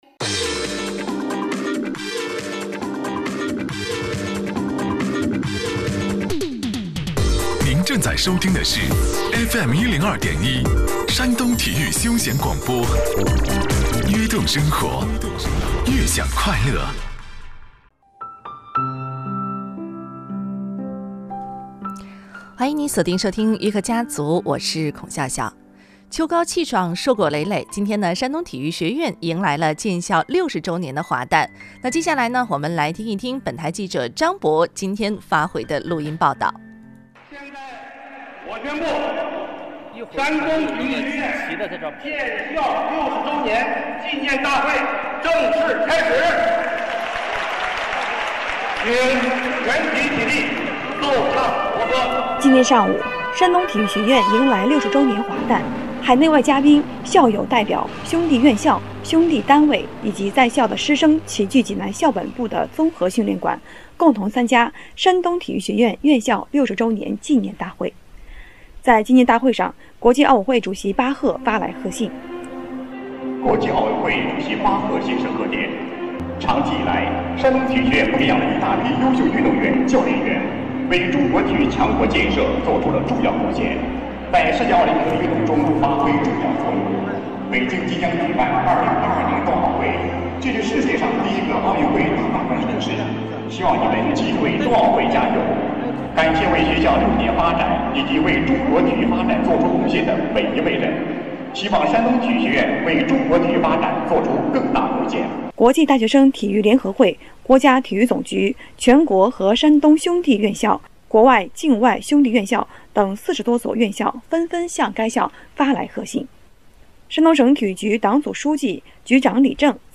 【山东体育休闲广播】采访体育学院建校60周年